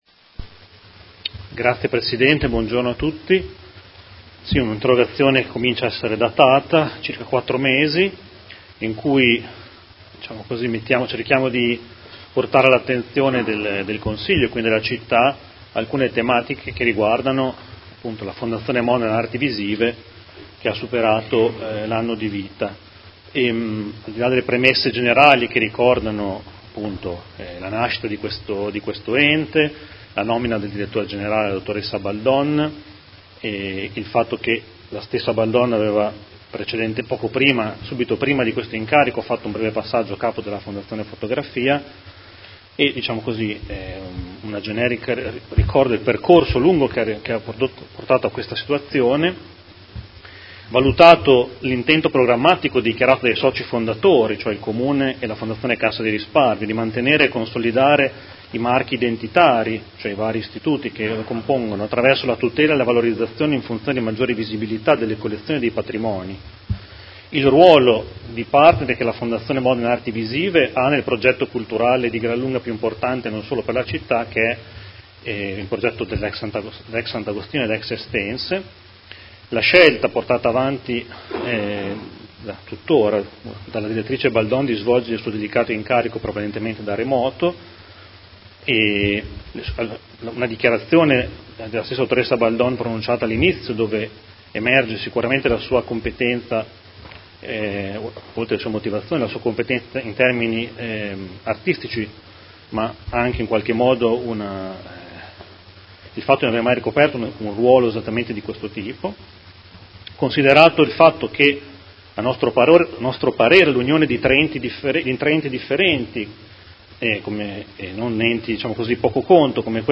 Seduta del 10/01/2019 Interrogazione del Gruppo Consiliare Movimento cinque Stelle avente per oggetto: Situazione FMAV